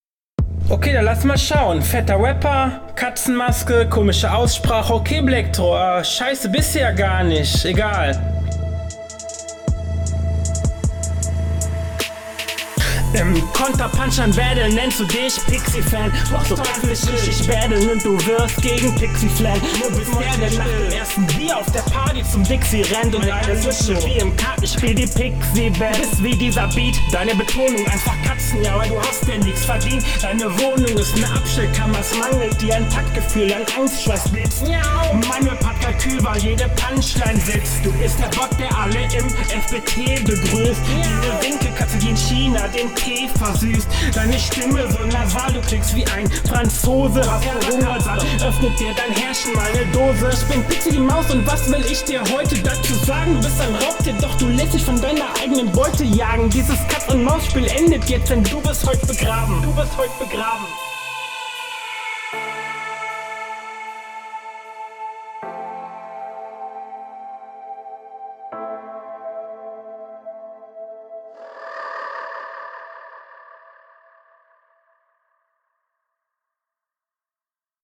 Flow: Ist geil hat auf den beat einfach was besonderes Text: für bronze sehr gut …
Flow: ist öfters offbeat und die doubles und die adlips sind sehr schlecht gesetzt Text: …